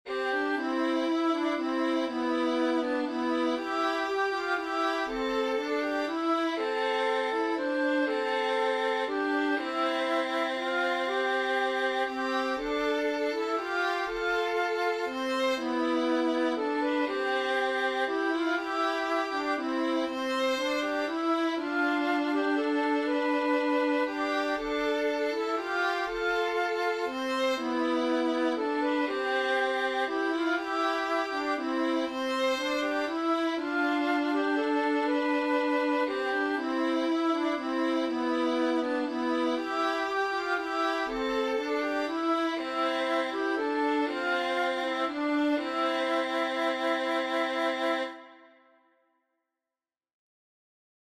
(trad. Irland)
Ein irisches Lied.
Es ist eine getragene Melodie!
Melodie mit 2. Stimme
RaglanRoad-2stimmig-C.mp3